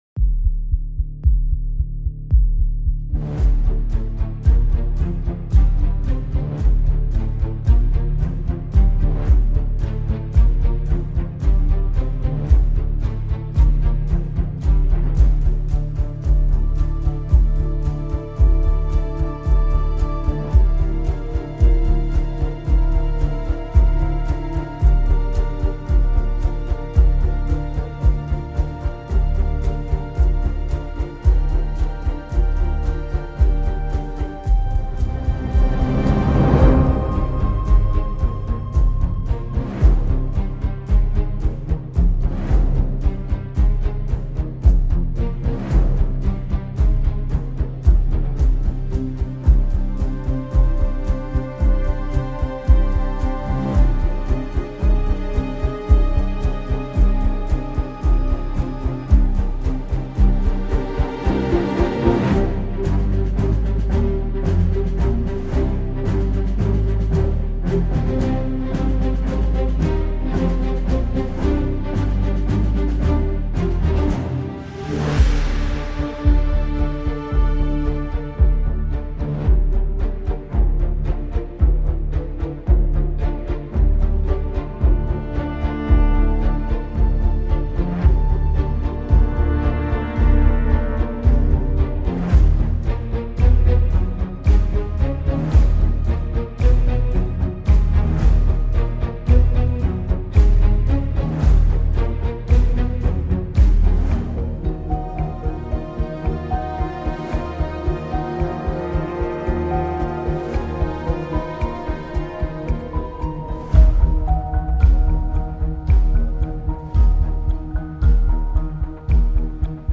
mention spéciale aux glissendi sinueux du requin